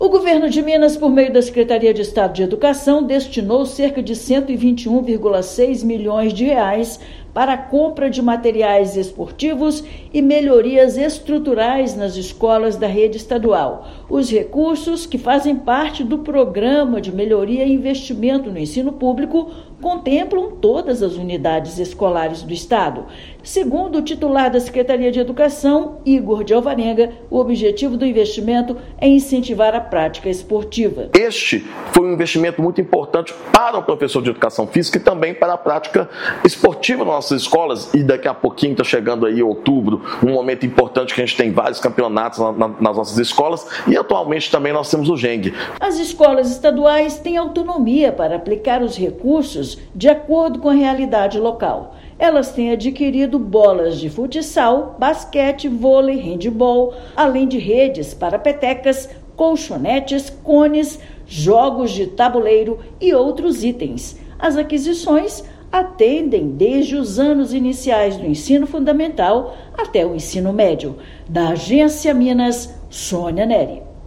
[RÁDIO] Governo de Minas impulsiona esporte para estudantes com a aquisição de kits pelas escolas
Investimento de R$ 121 milhões do Premiep já transforma as aulas de Educação Física e incentiva a participação dos alunos nos Jogos Escolares de Minas Gerais. Ouça matéria de rádio.